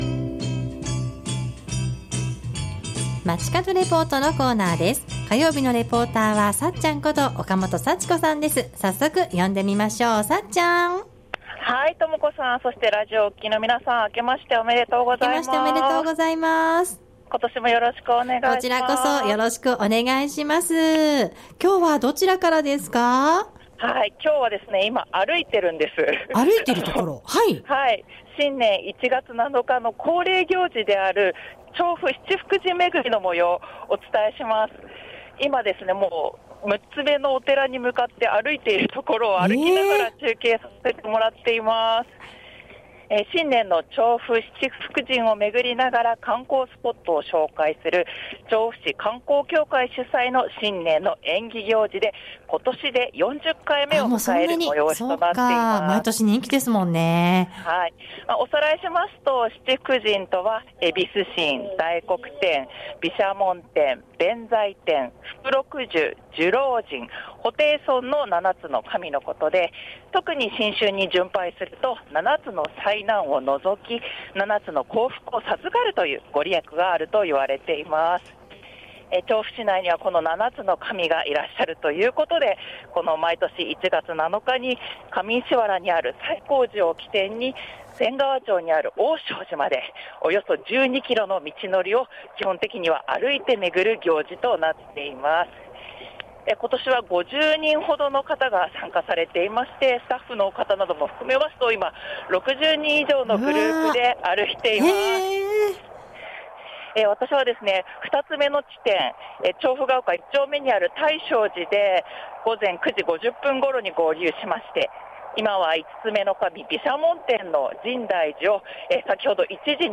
大正寺 中継は本日開催の新年の恒例行事「第40回 調布七福神めぐり」の模様を歩きながらお伝えしました。
放送では中継前に伺った参加者の方の感想などもご紹介しています。